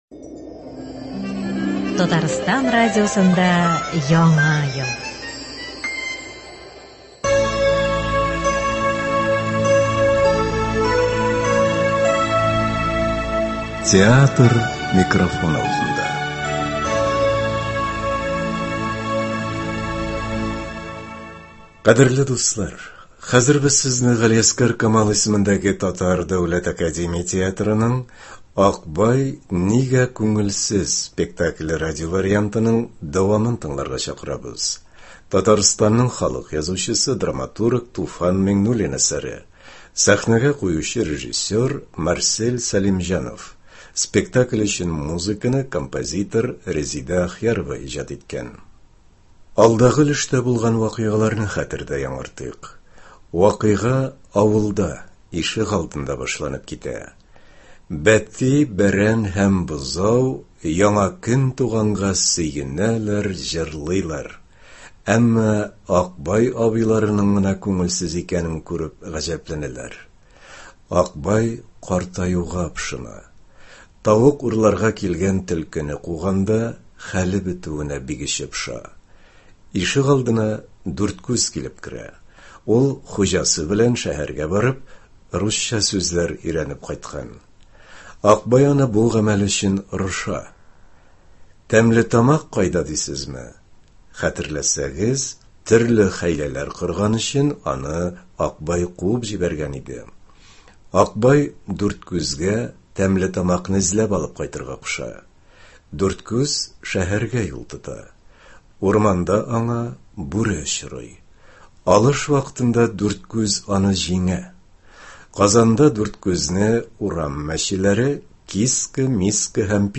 Кадерле дуслар! Бүген без сезнең игътибарга Г.Камал исемендәге Татар Дәүләт академия театрының “Акбай нигә күңелсез” спектакленең радиовариантын тәкъдим итәбез.
Рольләрне Г.Камал исемендәге Татар Дәүләт академия театры артистлары башкара.